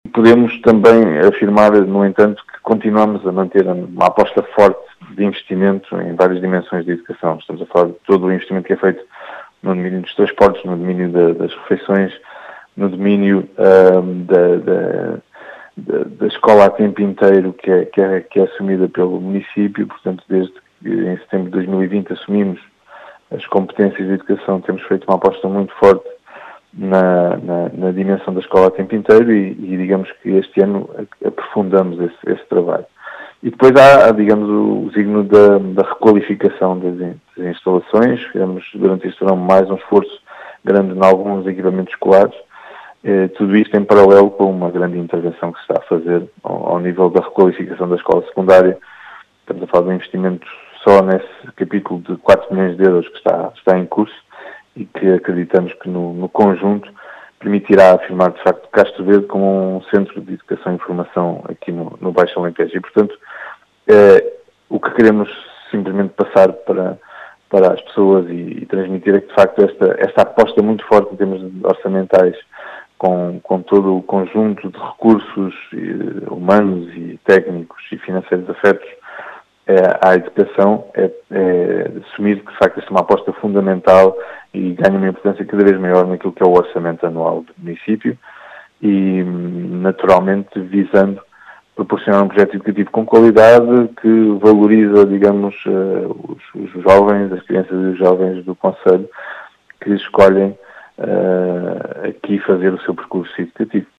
As explicações foram deixadas na Rádio Vidigueira, por David Marques, vereador da Câmara Municipal de Castro Verde, que fala numa “aposta fundamental” da autarquia.